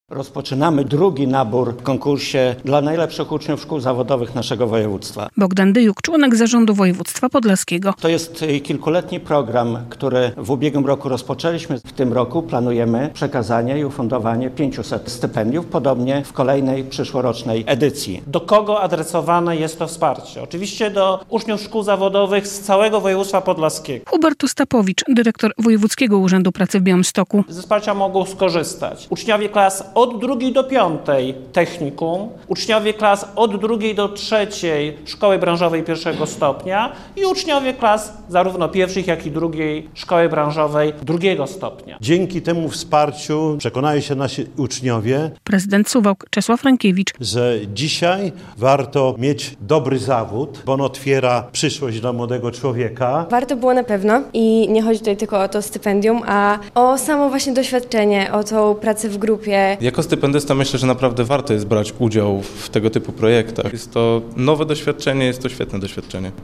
Stypendia dla uczniów szkół zawodowych - relacja